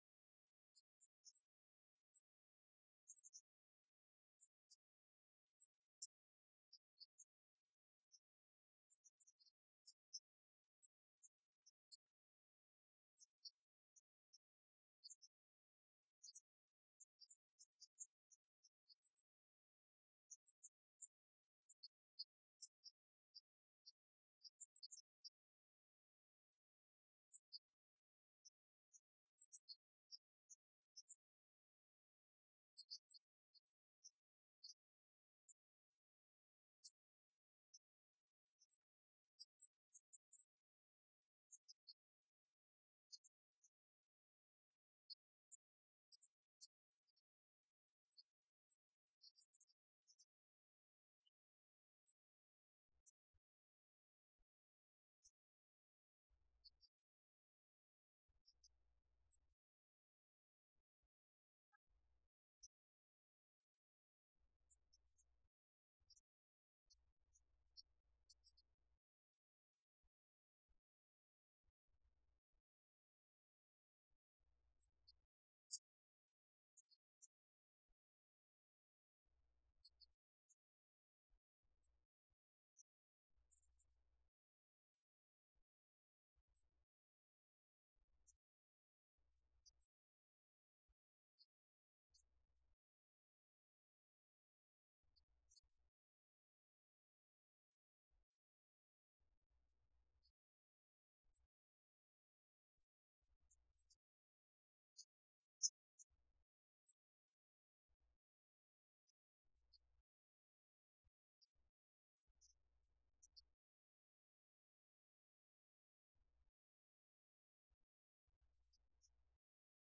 Christmas Eve 2024 Passage: Luke 2:1-20 Service Type: Christmas Eve « From Beginning To Babel